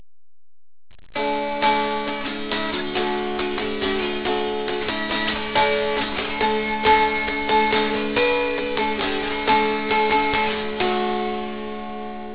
The instrument in the photograph is an Appalachian or American dulcimer (pronounced "dull-simmer") instantly recognisable by its traditional "hourglass" shape.
Dulcimer Sound Clips
You can hear the unusual sound produced by the drone strings in the soundclip.